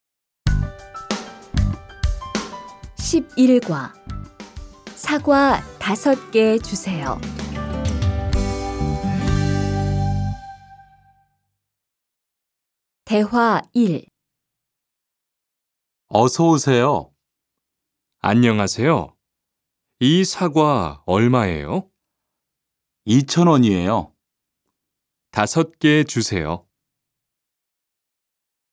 Berikut adalah percakapan di toko buah. Tuan sedang bertanya tentang harga buah kepada penjual.